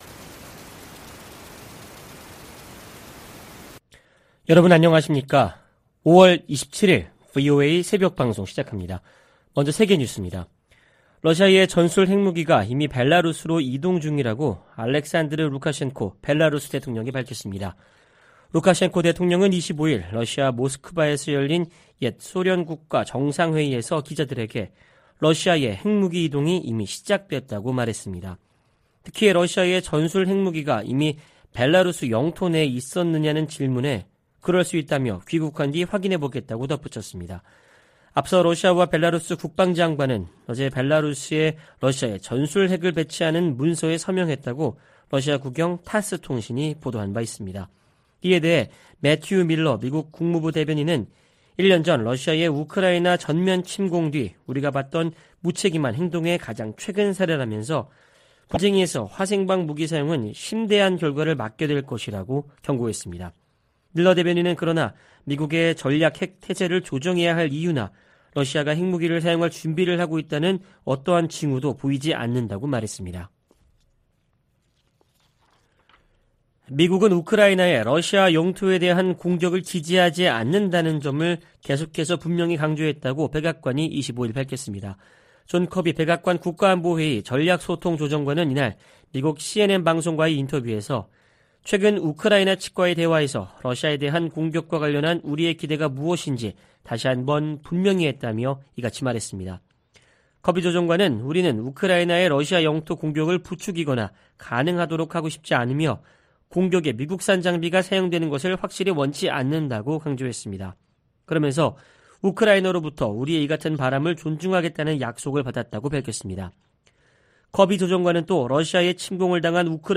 VOA 한국어 '출발 뉴스 쇼', 2023년 5월 27일 방송입니다. 한국이 자력으로 실용급 위성을 궤도에 안착시키는데 성공함으로써 북한은 우주 기술에서 뒤떨어진다는 평가가 나오고 있습니다. 워싱턴 선언은 한국에 대한 확정억제 공약을 가장 강력한 용어로 명시한 것이라고 미 고위 당국자가 평가했습니다. 미국은 중국의 타이완 공격을 억제하기 위해 동맹국들과 공동 계획을 수립해야 한다고 미 하원 중국특별위원회가 제언했습니다.